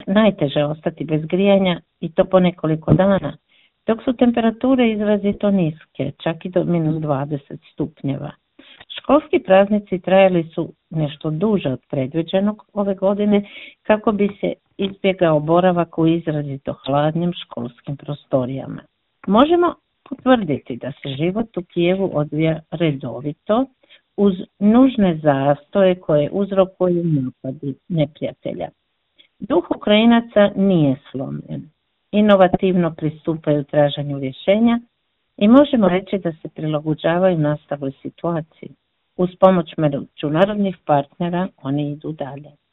Tim povodom u telefonskom Intervjuu Media servisa ugostili smo hrvatsku veleposlanicu iz Kijeva Anicu Djamić